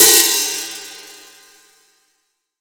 Index of /90_sSampleCDs/AKAI S6000 CD-ROM - Volume 3/Hi-Hat/14INCH_FLANGE_HI_HAT